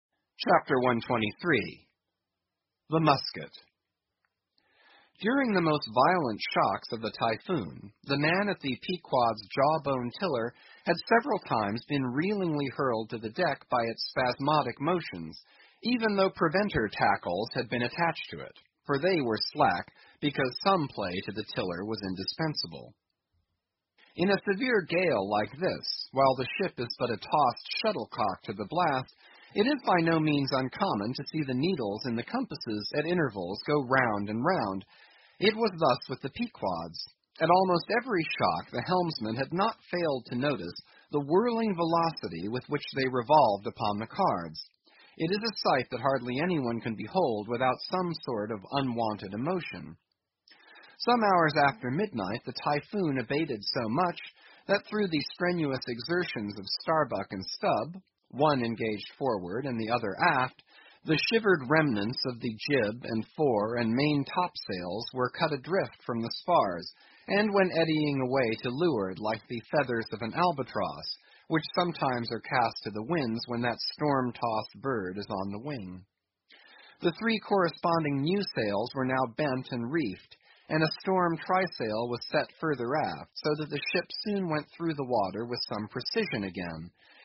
英语听书《白鲸记》第952期 听力文件下载—在线英语听力室